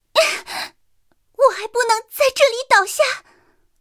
三号中破语音.OGG